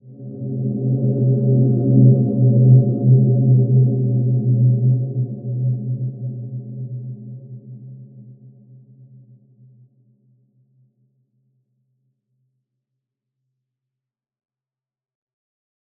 Wide-Dimension-C2-f.wav